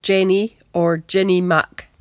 [JENni or JINni muhk]